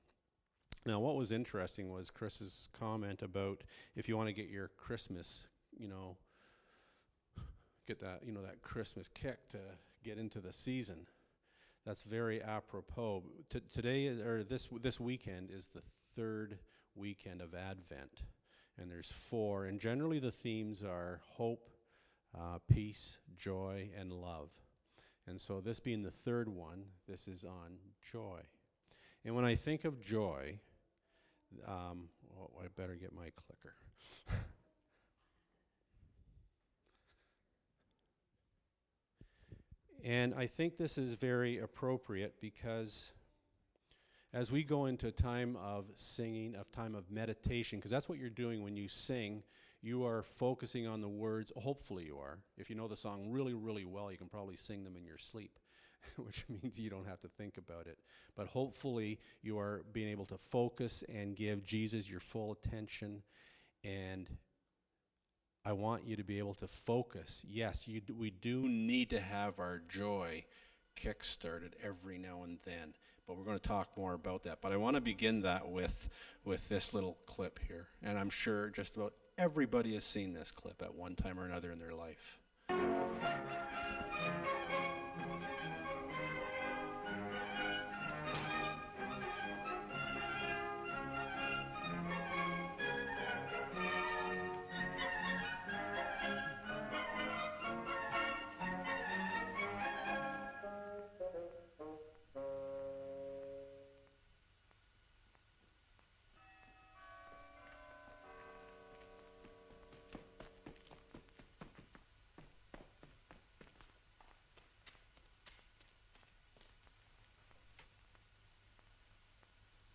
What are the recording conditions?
Service Type: Friday Nights